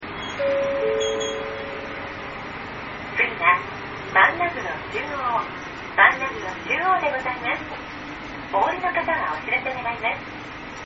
花畔中央MpegAudio(42.8kb)   ばんなぐろ